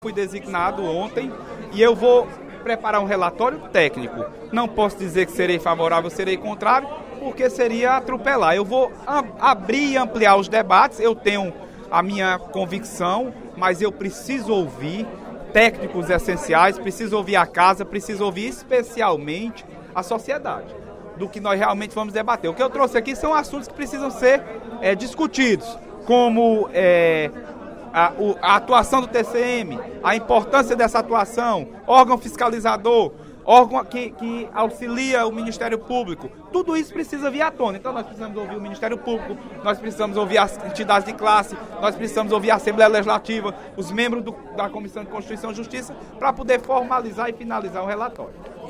O deputado Leonardo Araújo (PMDB) anunciou, durante o primeiro expediente da sessão plenária desta quinta-feira (01/06), que será o relator da proposta de emenda constitucional (PEC) que propõe a extinção do Tribunal de Contas dos Municípios (TCM).